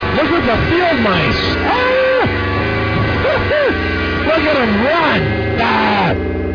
Vocals, Trash Cans,
Tornado Sounds